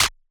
[ET] Dro Clap.wav